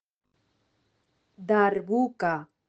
Audio file of the word "Darbuka"